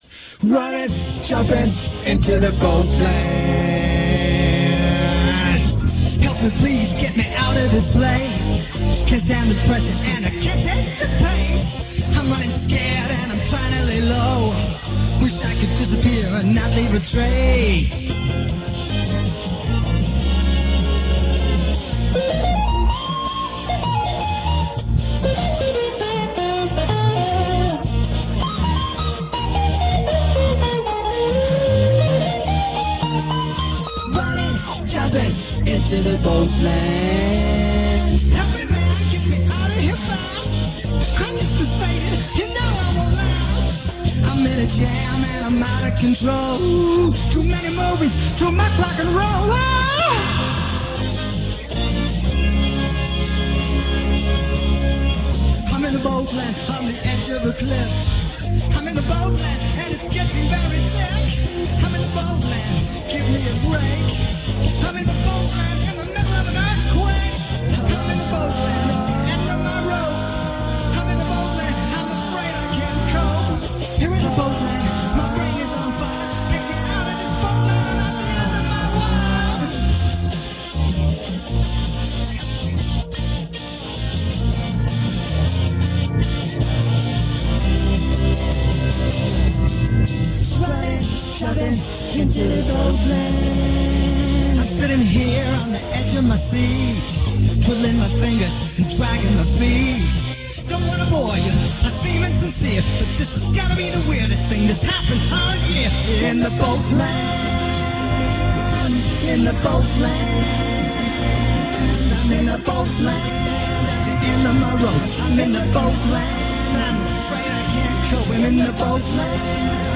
Frenetic guitar and organ solos until explosion ends song